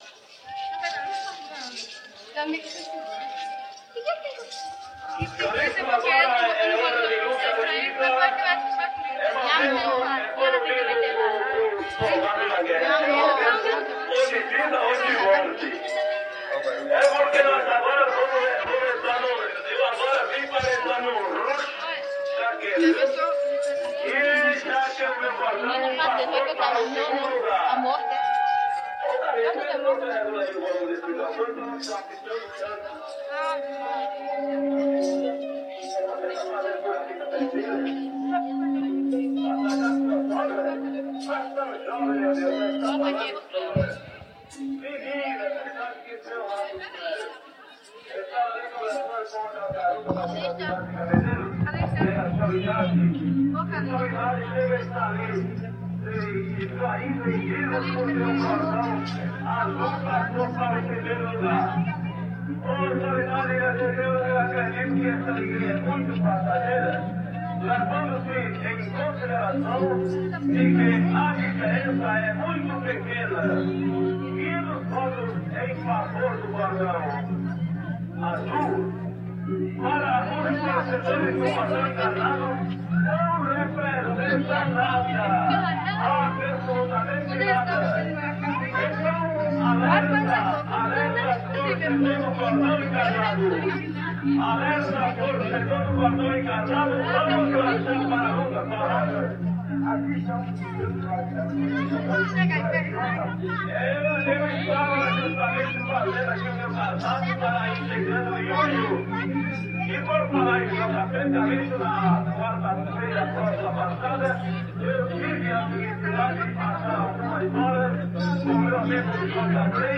Série Brincantes - Autos e Danças
Acompanhado por bandolim, cavaquinho, violão e pandeiro.